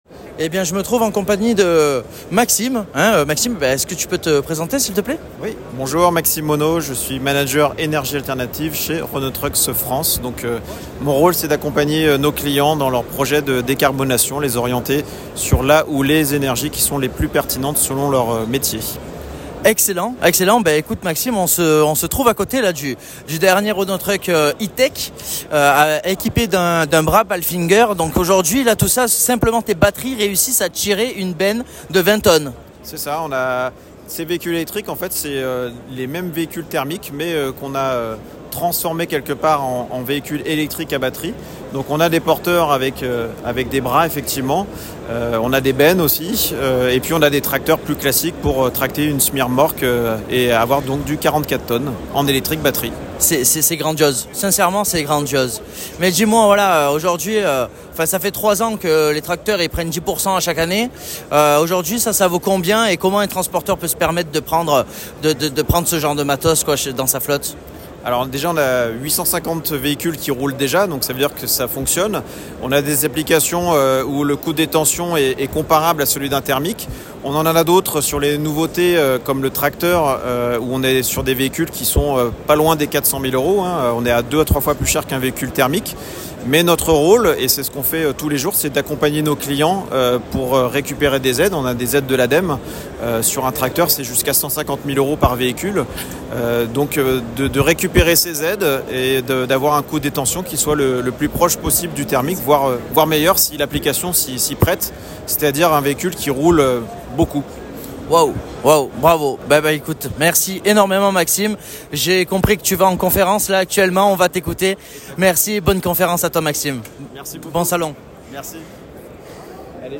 Le 23/11/2023 – EUREXPO Chassieu – SOLUTRANS
INTERVIEW